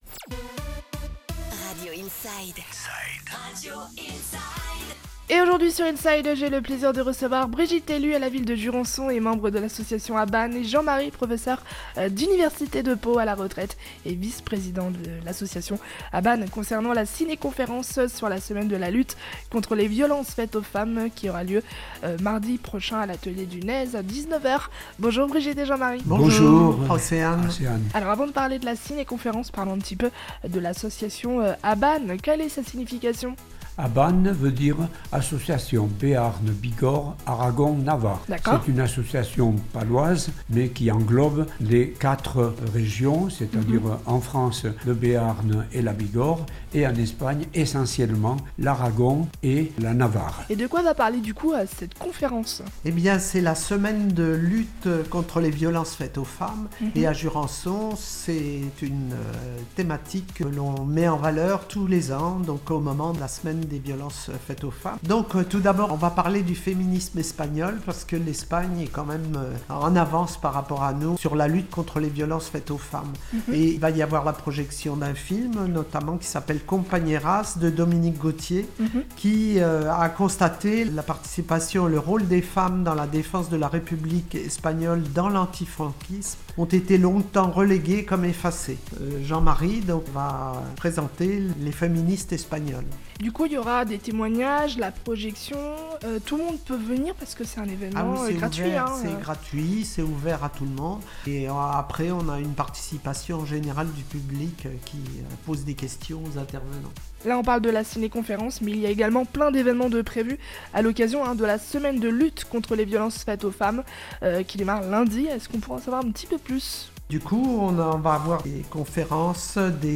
INSIDE : Réécoutez les flash infos et les différentes chroniques de votre radio⬦